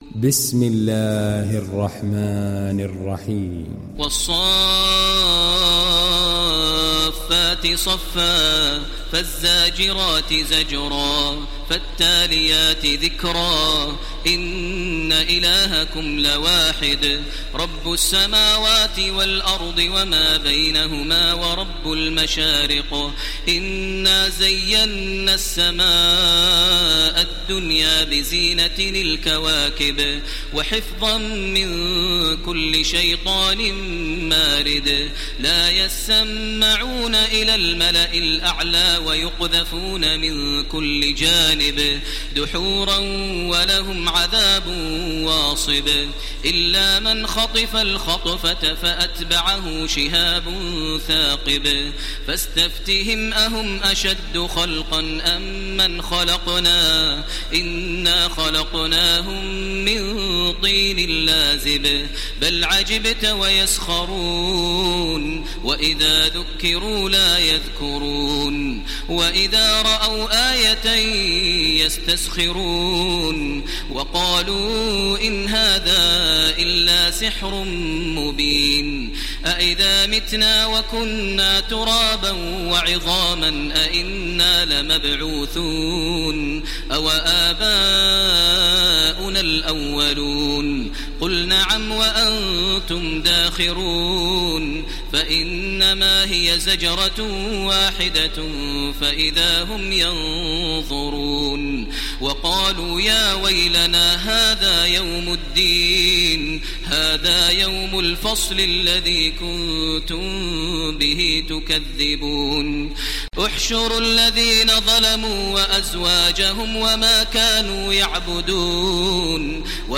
İndir Saffet Suresi Taraweeh Makkah 1430